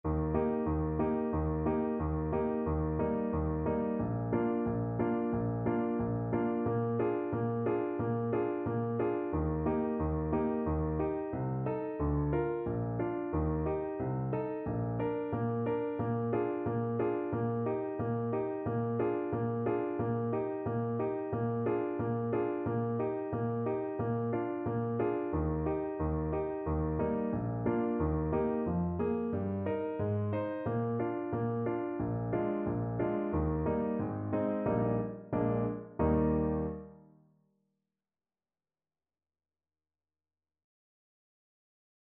MTB Singing Vaccai High Voice Accompaniment Recordings - MTB Exams
Lesson-X-Introduction-to-the-Turn-High-Voice-accompaniment.mp3